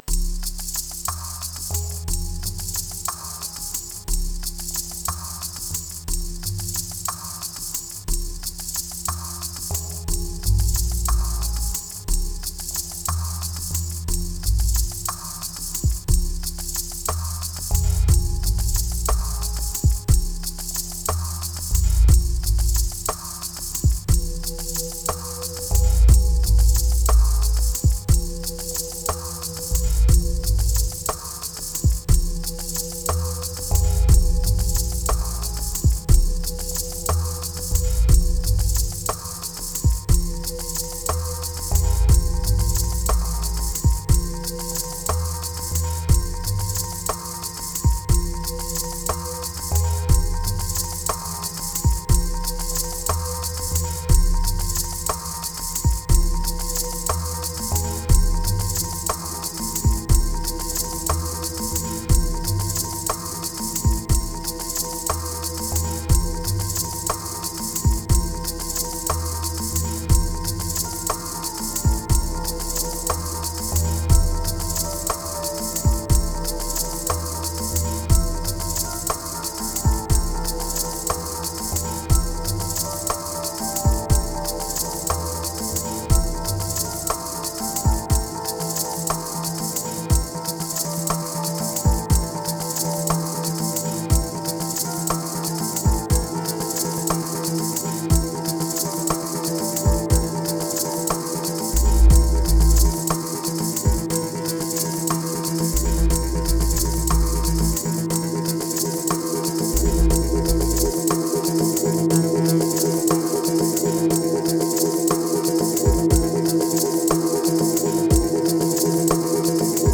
502📈 - 83%🤔 - 60BPM🔊 - 2021-10-08📅 - 282🌟
Triphop Beats Moods Seldom Attic Crush Enclosure Reveal Heal